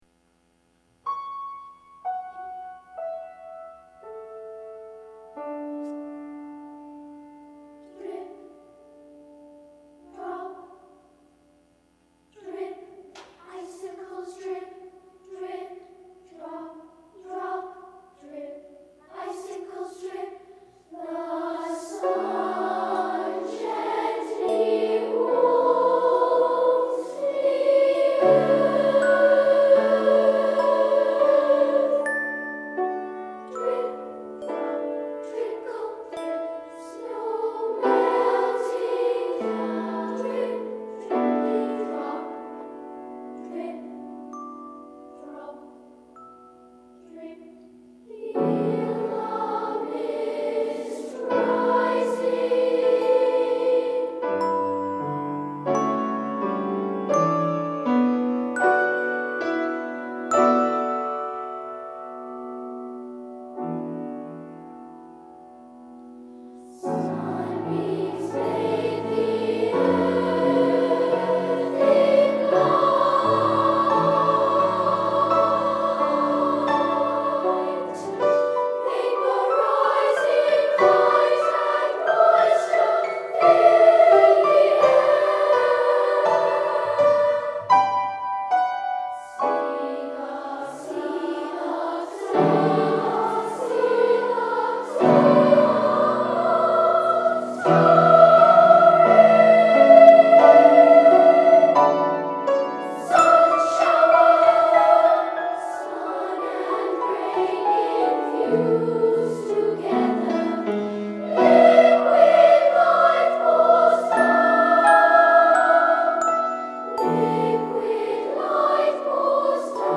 SA (2 voices young children) ; Octavo score.
Tonality: F sharp minor